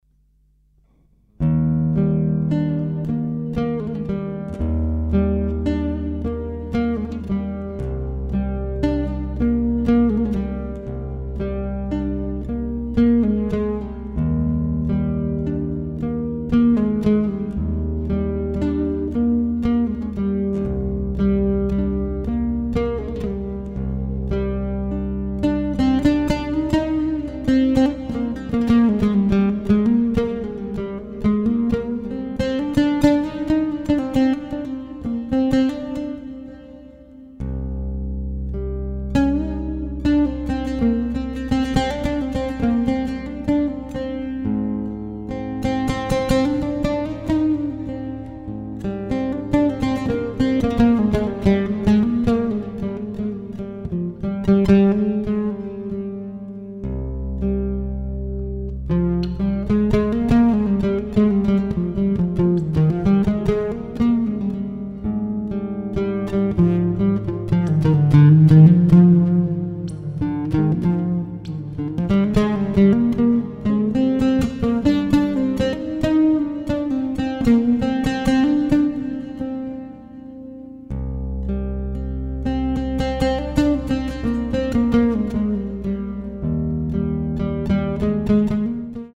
Contemporary , Japanese Ambience
, Oud , Relaxing / Meditative